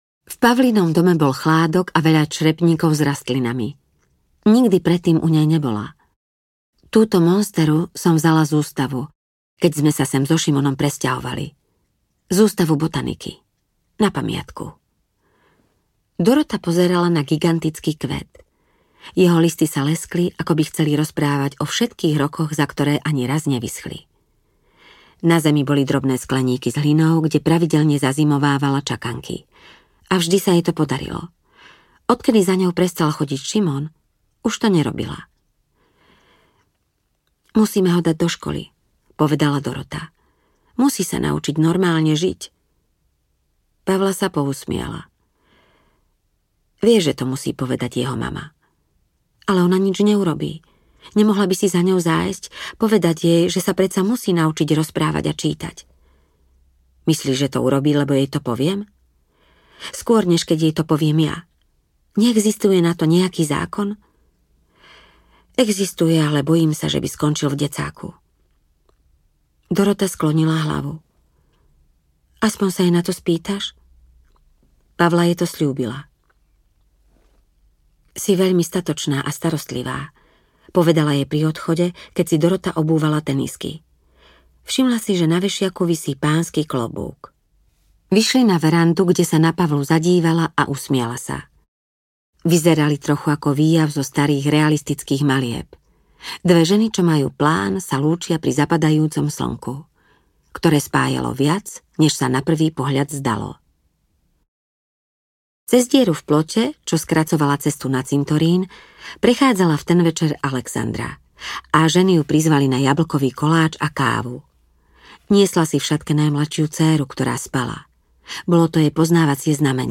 Šeptuchy audiokniha
Ukázka z knihy